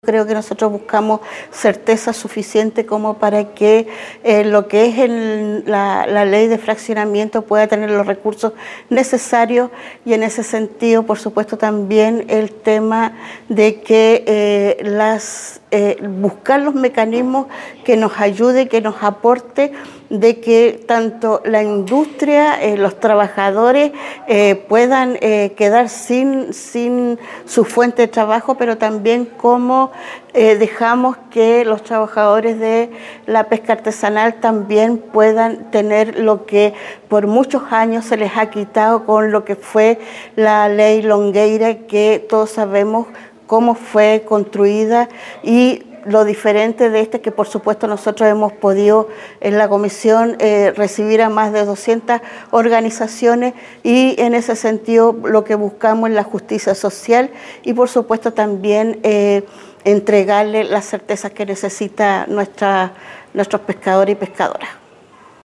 La diputada María Candelaria Acevedo (PC) hizo un llamado a “buscar los mecanismos que ayuden a que tanto los trabajadores de la industria no pierdan sus empleos y que el sector artesanal pueda recuperar lo que se perdió con la Ley Longueira”.